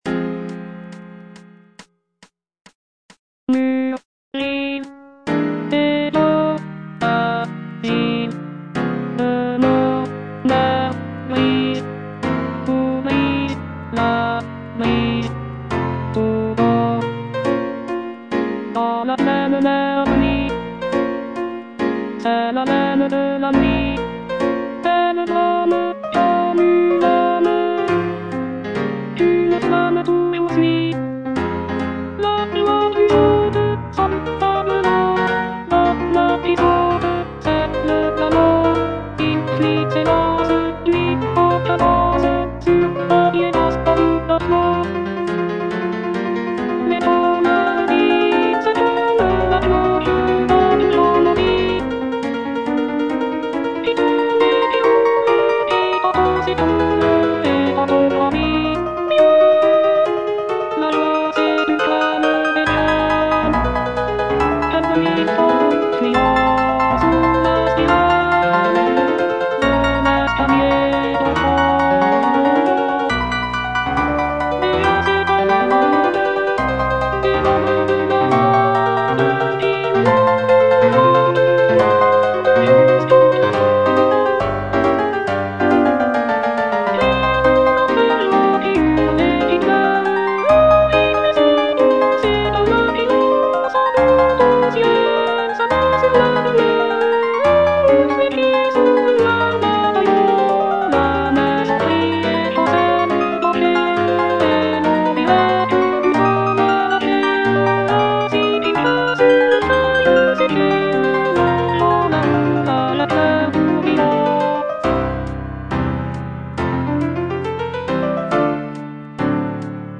G. FAURÉ - LES DJINNS Alto I (Voice with metronome) Ads stop: